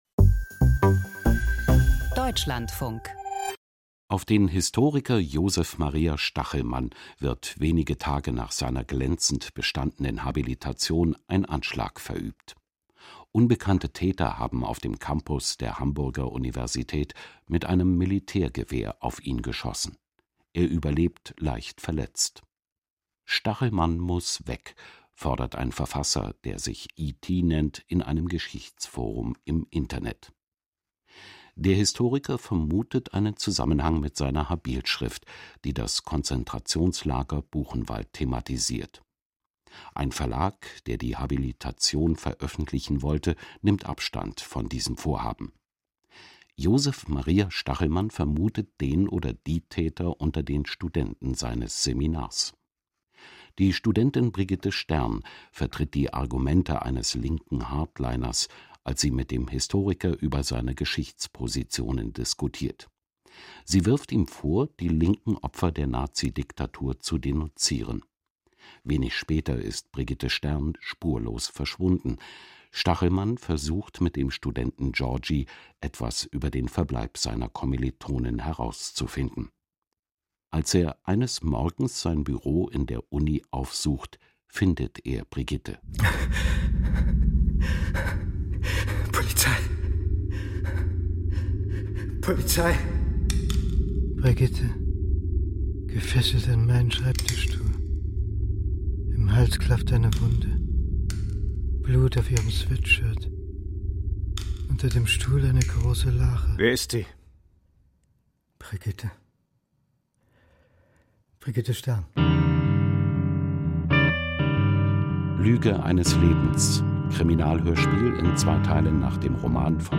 Aus dem Podcast Kriminalhörspiel Podcast abonnieren Podcast hören Podcast Krimi Hörspiel Die ganze Welt des Krimis in einem Podcast: Von Agatha Christie bis Donna Leon und Kommissar...